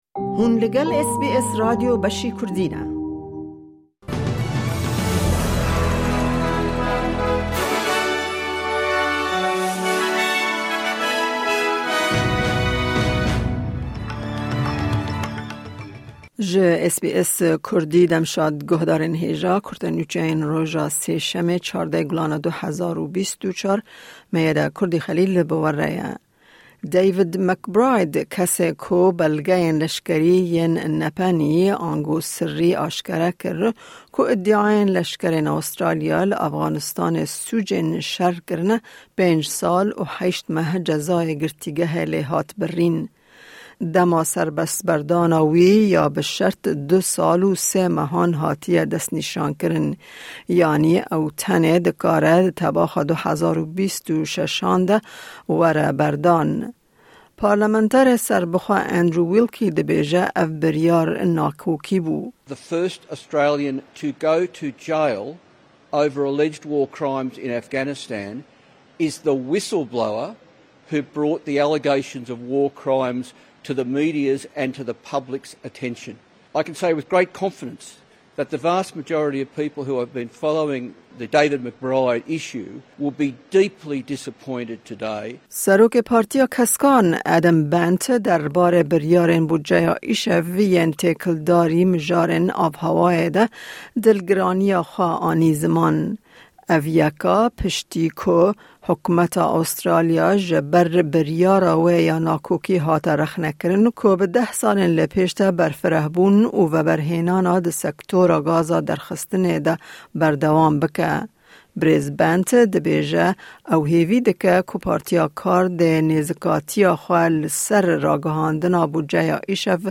Kurte Nûçeyên roja Sêşemê 14î Gulana 2024